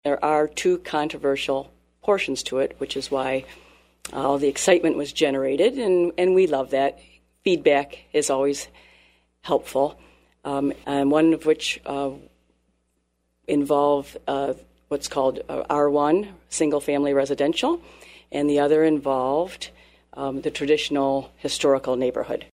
Fourth Ward Council Member Nicki Arendshorst explained what the biggest bones of contention are.